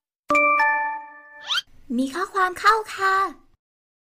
เสียงแจ้งเตือนไลน์ฟรี iOS iPhone
เสียงแจ้งเตือนไอโฟน iOS 17 เสียงน้าค่อม iPhone
หมวดหมู่: เสียงเรียกเข้า
กับเสียงแจ้งเตือนไลน์ที่มีข้อความเข้าค่ะสุดเก๋ ที่จะทำให้ทุกครั้งที่มีข้อความเข้ามา คุณจะไม่พลาดทุกการแจ้งเตือน!